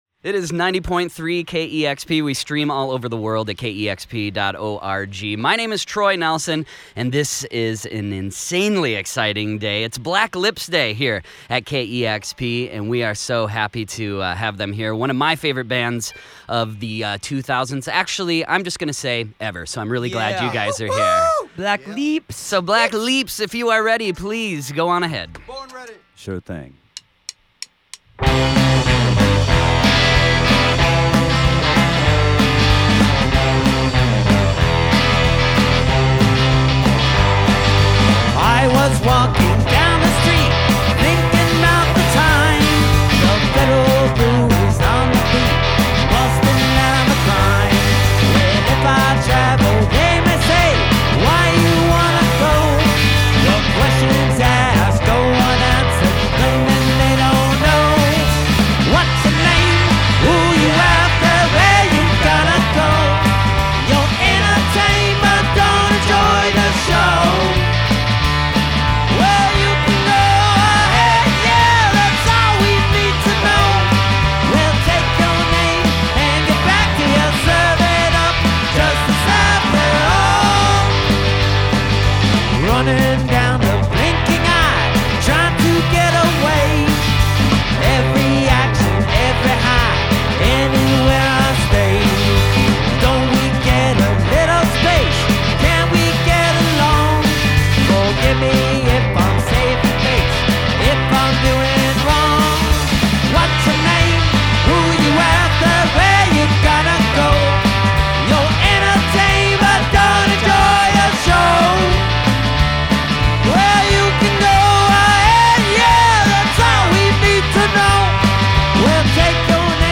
Atlanta garage rockers